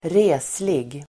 Ladda ner uttalet
Folkets service: reslig reslig adjektiv, tall Uttal: [²r'e:slig] Böjningar: resligt, resliga Synonymer: lång, stor Definition: högväxt Exempel: en reslig man (a tall man) towering adjektiv, hög , reslig